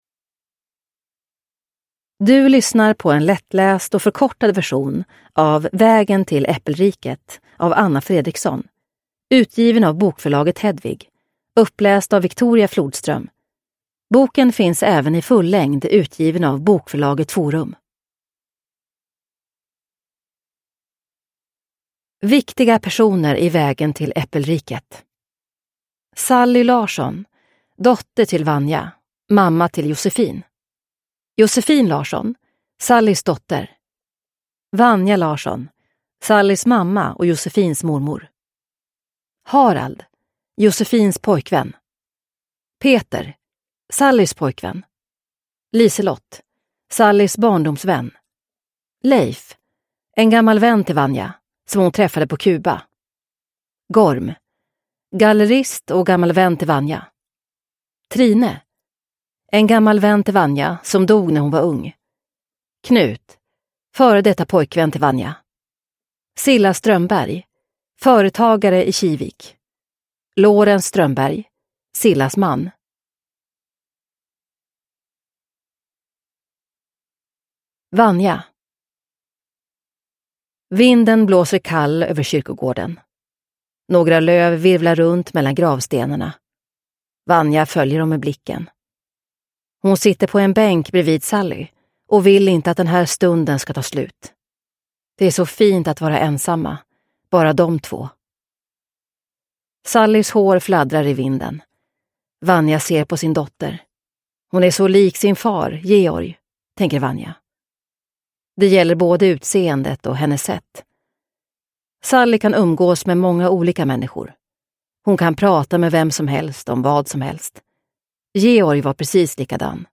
Vägen till äppelriket (lättläst) – Ljudbok